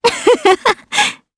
Ripine-Vox_Happy3_jp.wav